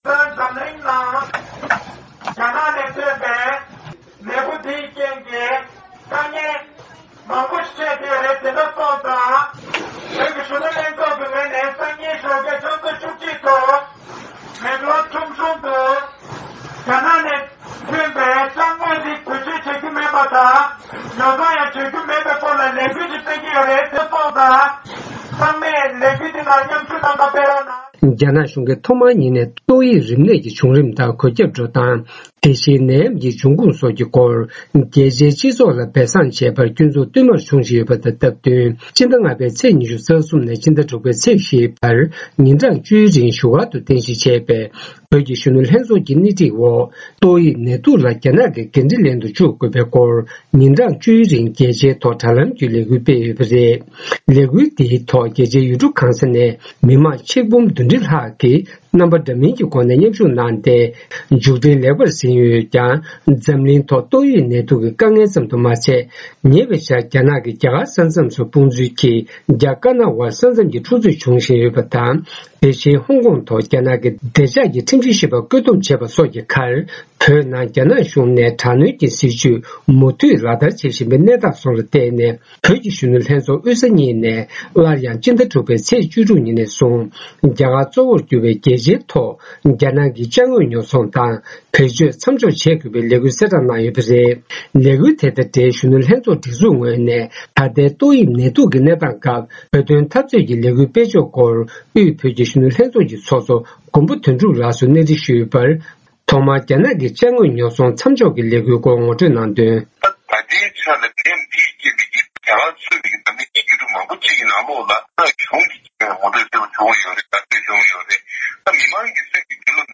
བཅར་འདྲི་ཕྱོགས་བསྒྲིགས